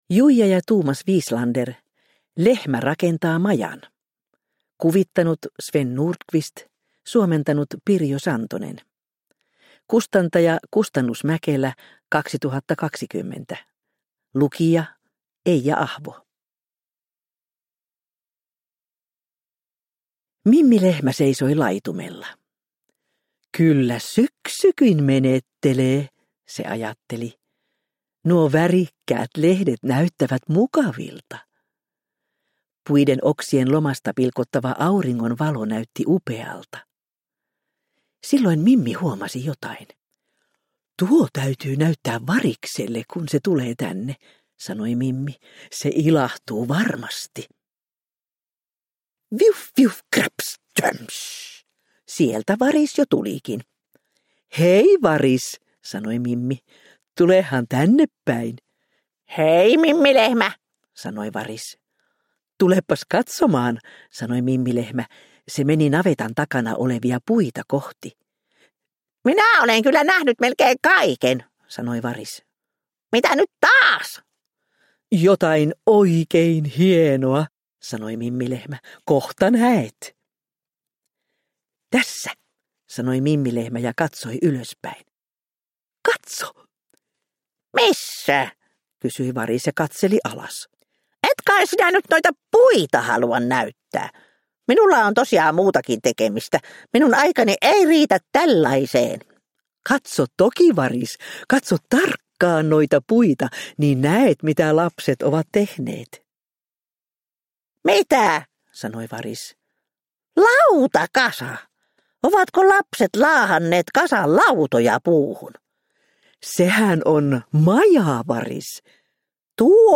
Lehmä rakentaa majan – Ljudbok – Laddas ner